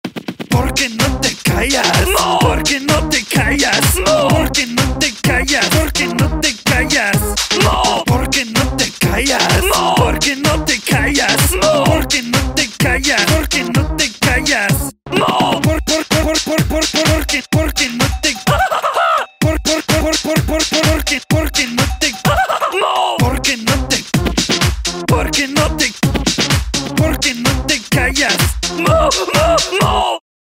Category: Ringtone